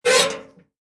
mailbox_open_2.mp3